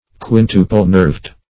Search Result for " quintuple-nerved" : The Collaborative International Dictionary of English v.0.48: Quintuple-nerved \Quin"tu*ple-nerved`\, Quintuple-ribbed \Quin"tu*ple-ribbed`\, a. (Bot.)